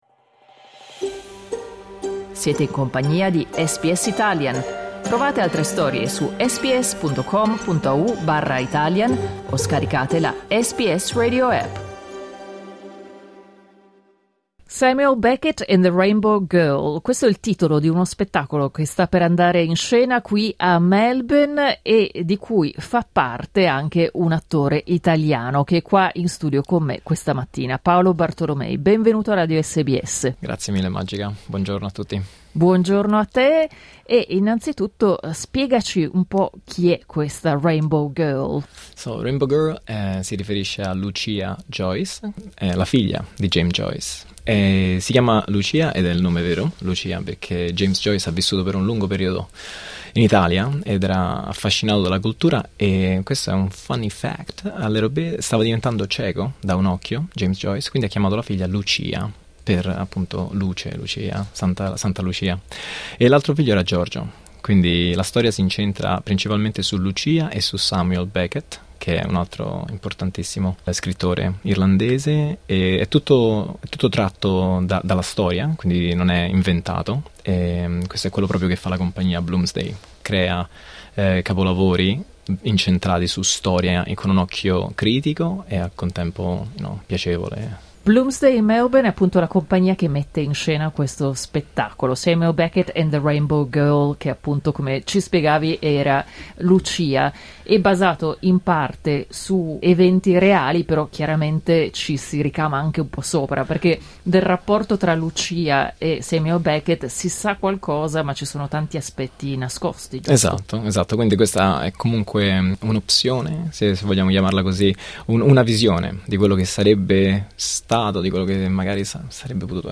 negli studi di SBS